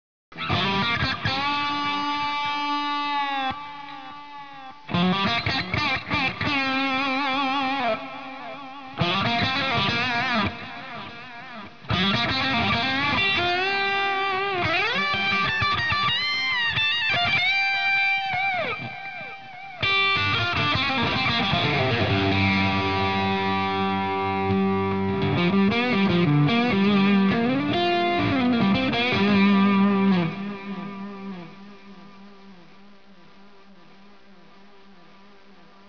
ESEMPIO AUDIO 02 - DELAY TAPE
Ping Pong Delay Tonex
02-DELAT-PING-PONG-2.wav